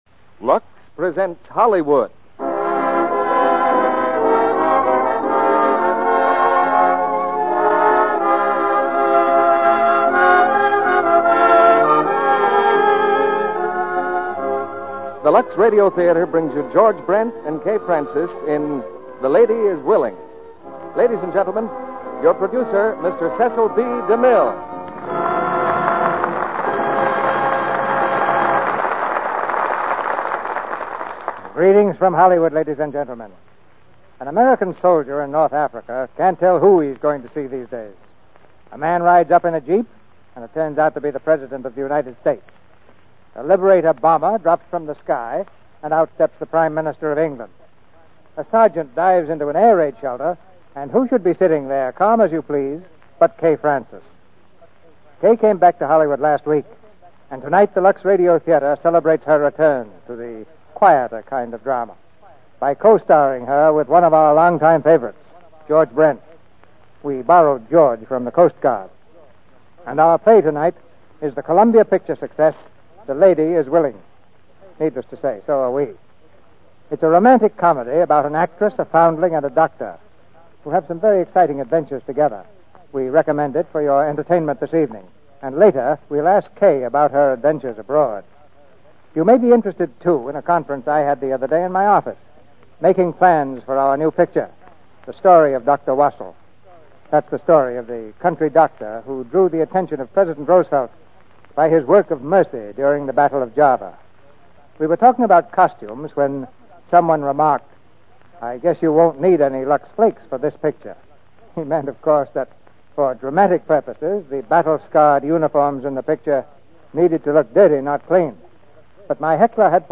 starring Kay Francis, George Brent, Arthur Q. Bryan
Lux Radio Theater Radio Show